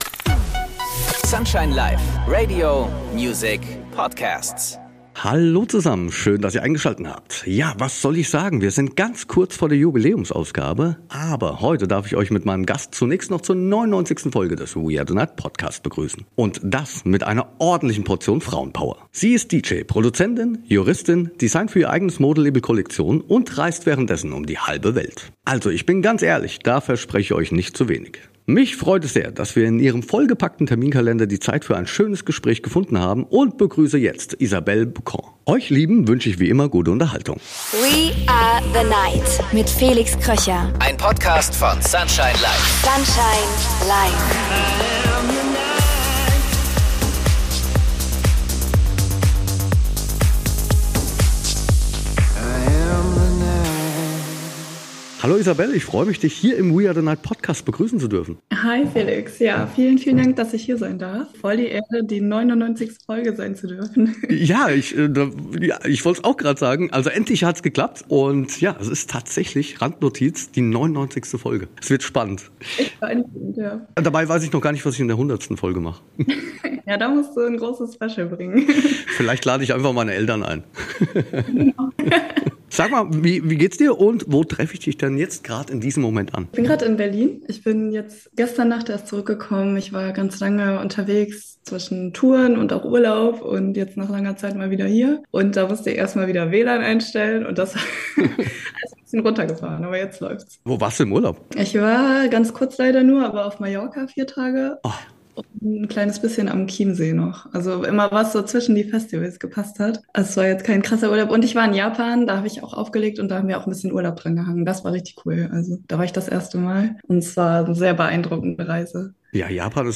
Zusammen mit DJ Kolleginnen, Veranstaltern, Managerinnen, Türstehern und vielen weiteren Gästen plaudert Felix in diesem Podcast unverblümt über seine Erlebnisse.